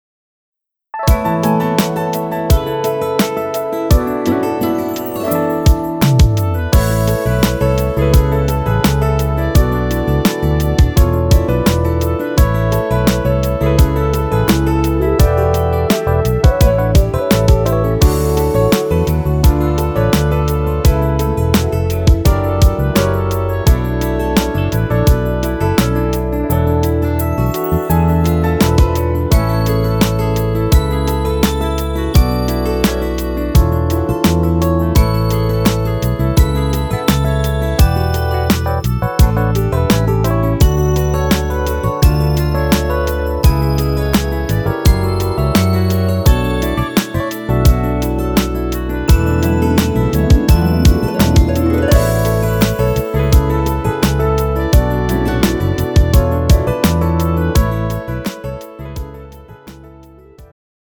음정 남자-2키
장르 축가 구분 Pro MR